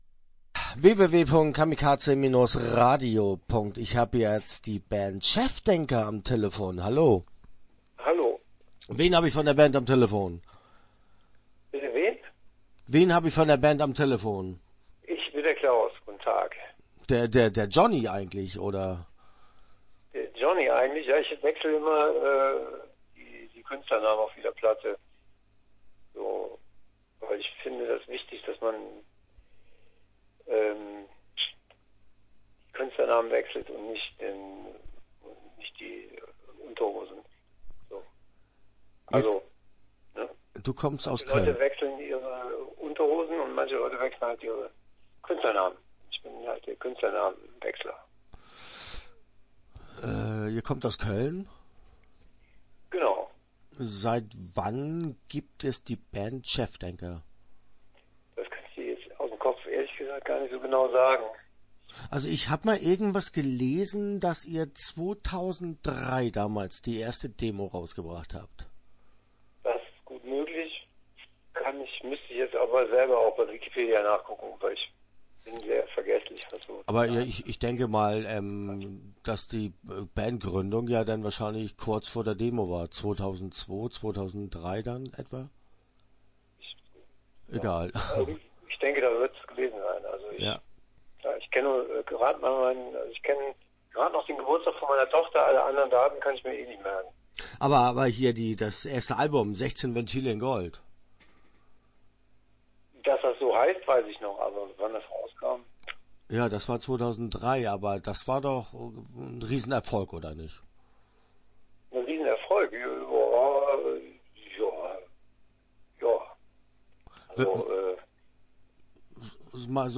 Start » Interviews » Chefdenker